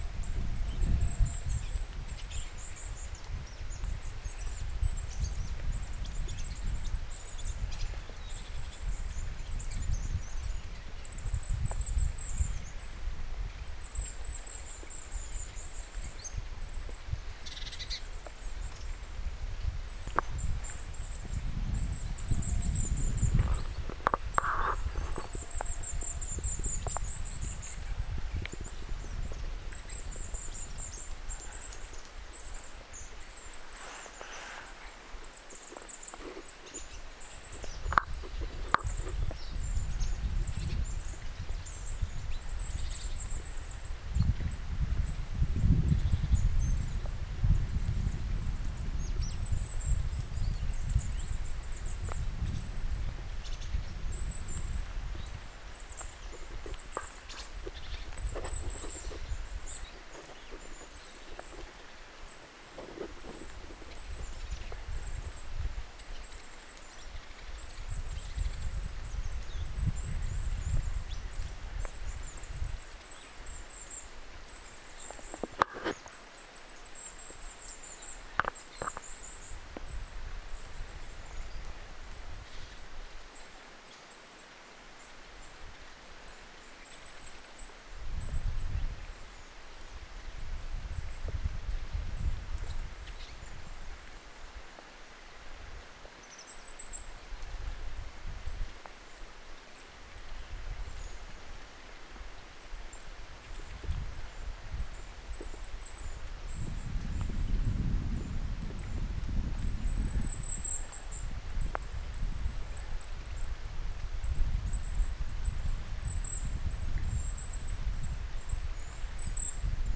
hippiäisten ääntelyä saaressa
hippiaisten_aantelya_saaressa.mp3